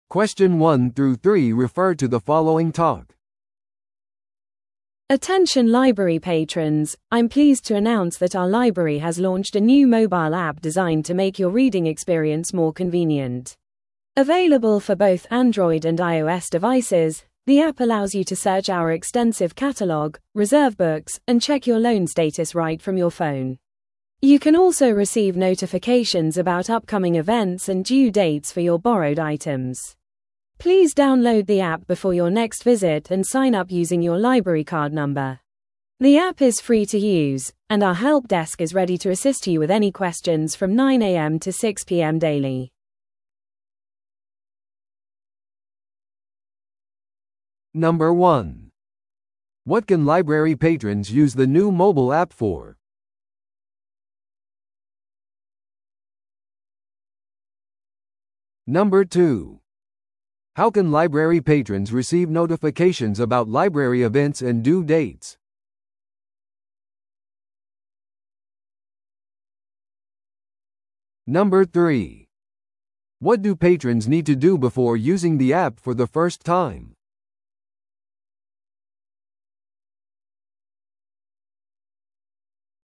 TOEICⓇ対策 Part 4｜図書館のモバイルアプリ – 音声付き No.041